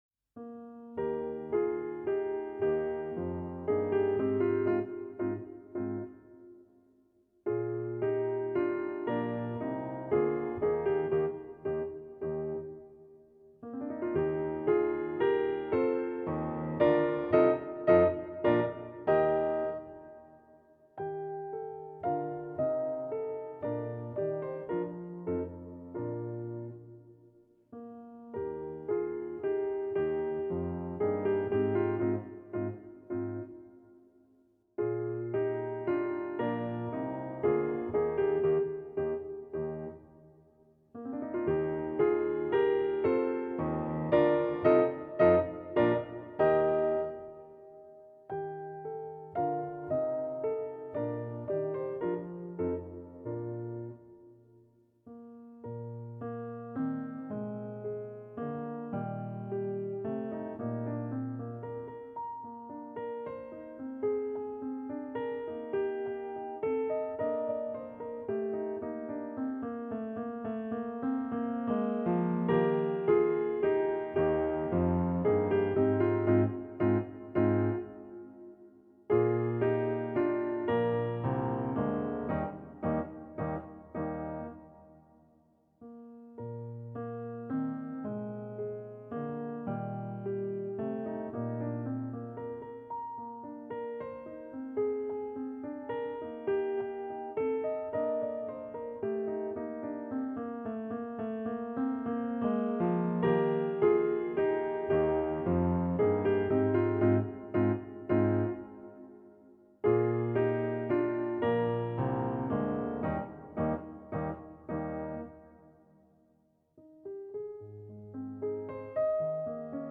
No parts available for this pieces as it is for solo piano.
3/4 (View more 3/4 Music)
Eb major (Sounding Pitch) (View more Eb major Music for Piano )
Moderato =c.144
Piano  (View more Easy Piano Music)
Classical (View more Classical Piano Music)